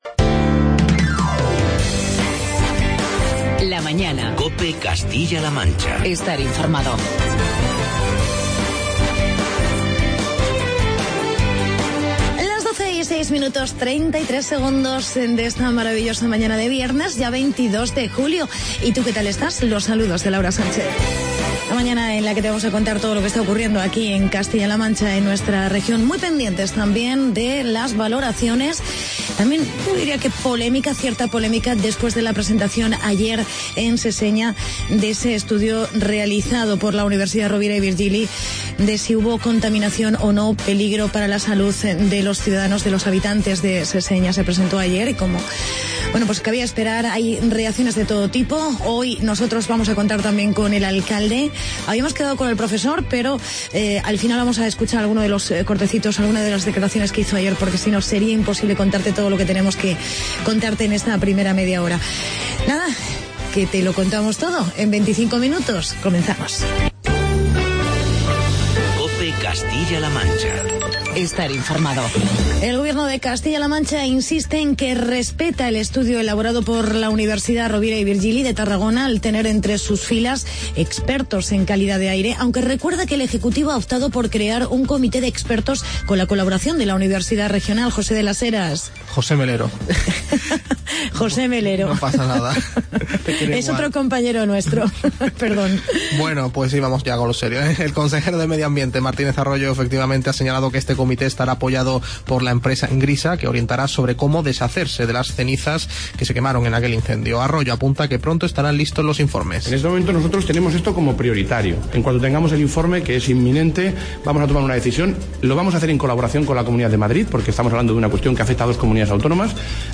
Entrevista a Carlos Velázquez, alcalde de Seseña sobre el informe universitario del incendio de la...